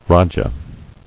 [ra·ja]